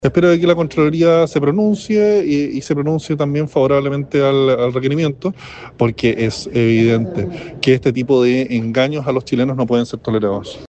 El jefe de bancada de los republicanos, el diputado Luis Sánchez, planteó que esperan que la Contraloría se pronuncie favorablemente al requerimiento.